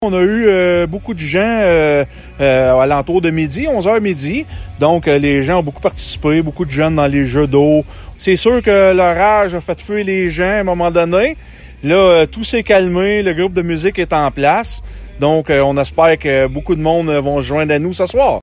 Le maire de Gracefield, Mathieu Caron, en parle :